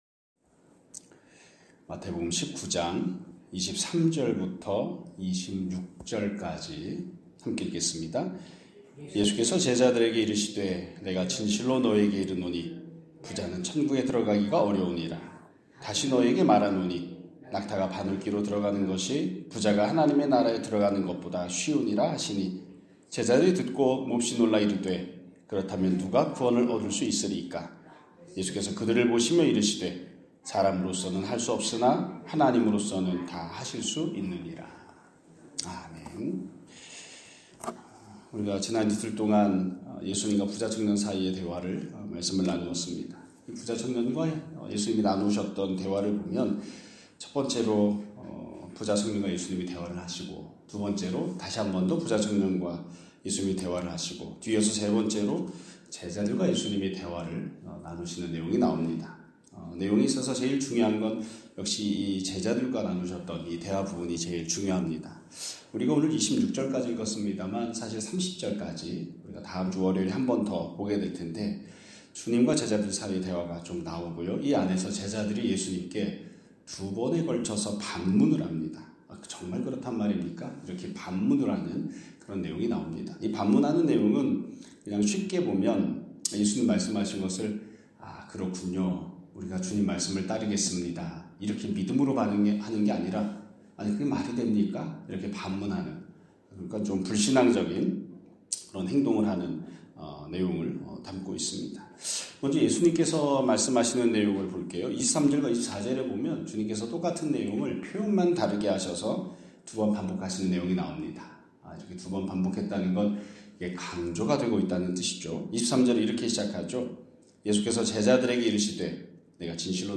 2026년 1월 9일 (금요일) <아침예배> 설교입니다.